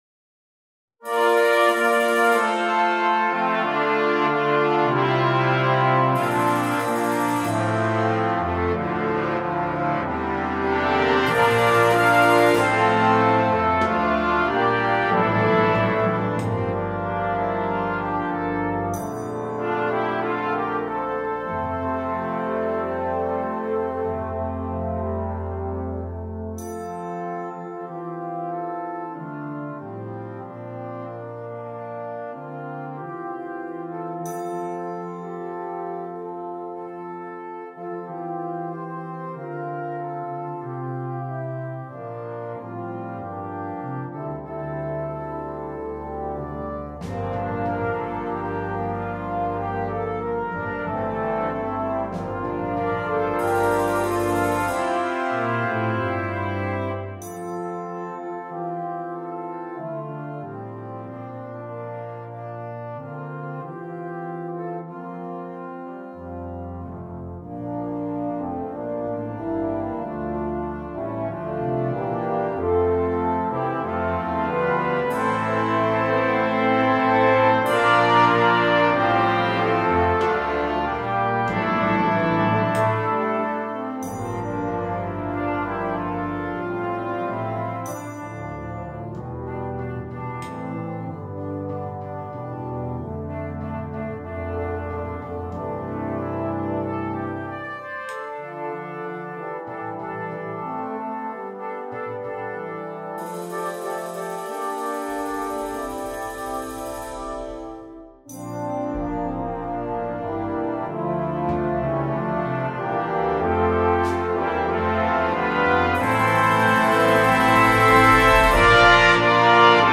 2. Banda de metales
Banda completa
Himnos